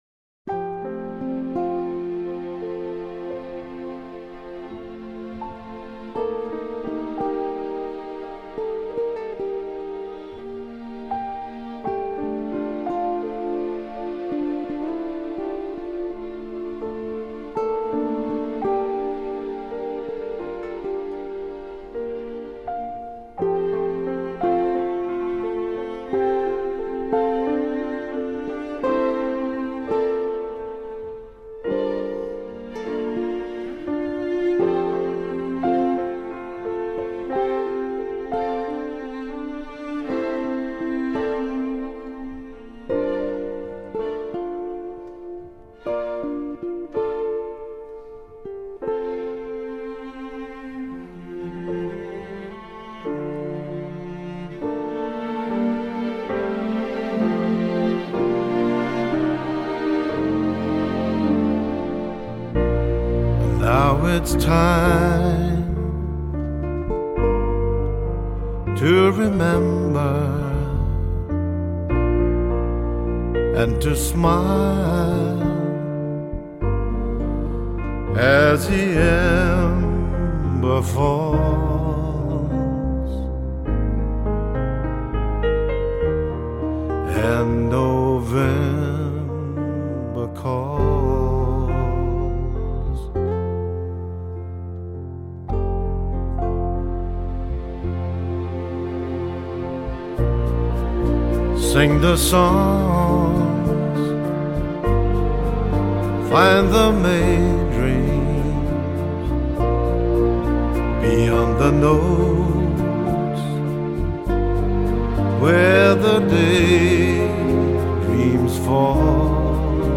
(с музыкой совсем печально....)
Нежная красивая музыка... и полет осеннего письма...память писем.